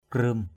/krɯ:m/ (d.) tre = bambou. bamboo.